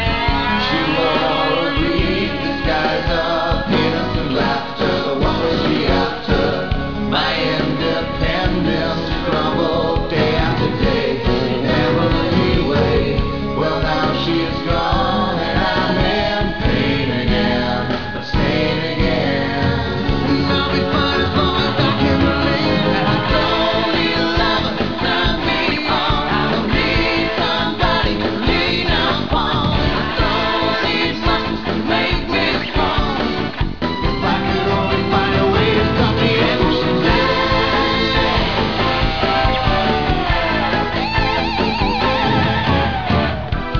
lead and backing vocals
keyboards, backing vocals, acoustic guitar, bass and drums
lead and rhythm guitars